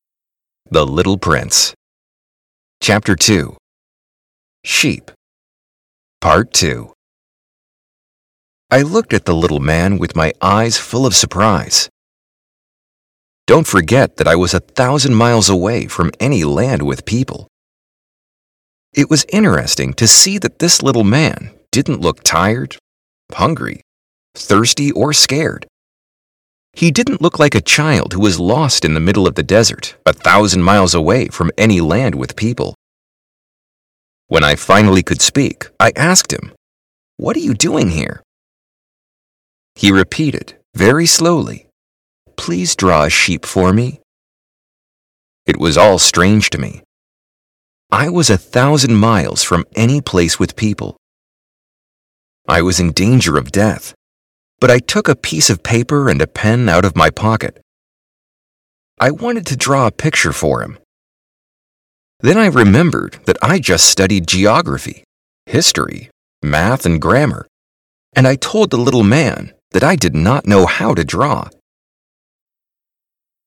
Shadowing